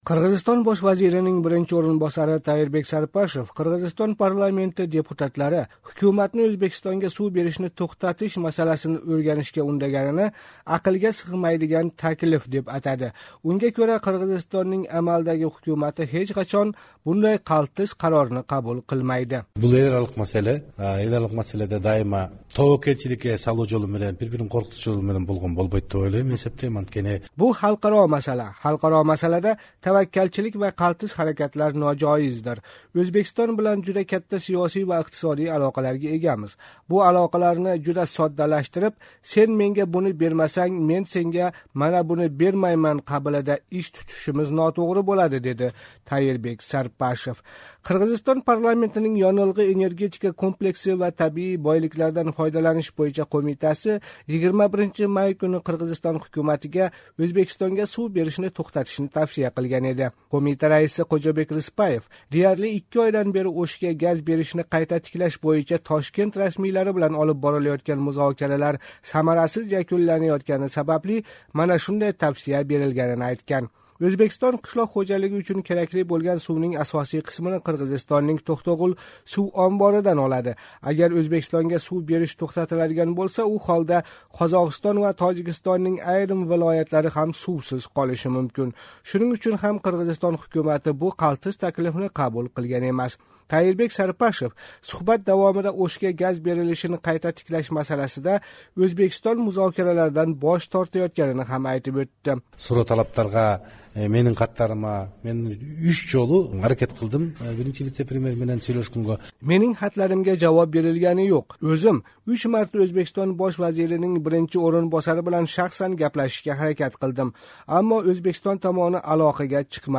Қирғизистон Бош вазирининг биринчи ўринбосари Тайирбек Сарпашев Озодлик радиоси қирғиз хизматига берган интервьюсида мана шу гапни айтди. Қирғизистон парламенти бир неча ҳафта аввал Ўзбекистон Ўшга деряли икки ойдан бери газ бермаётгани муносабати билан Қирғизистон ҳукуматига Ўзбекистонга сув беришни тўхтатишни тавсия қилган эди.